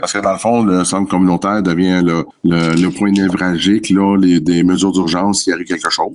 En terminant l’entrevue, le maire a mentionné que la Municipalité travaillait sur d’autres projets, mais qu’il était encore trop tôt pour en parler.